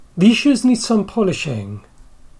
/z/ (followed by /ʃ/) becomes /ʃ/